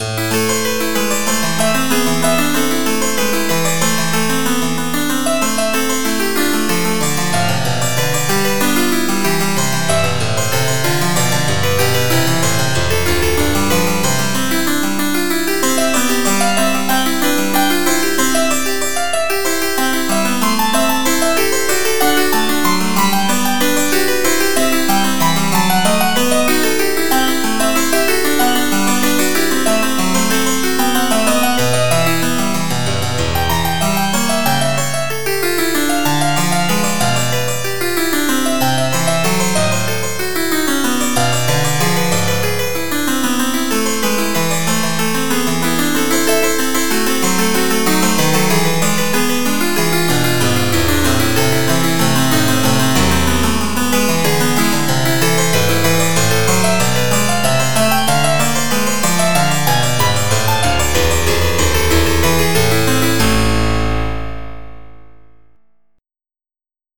MIDI Music File
Bach Invention No. 13. Copyright 1994, Cambium Development Type General MIDI